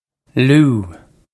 Loo
This is an approximate phonetic pronunciation of the phrase.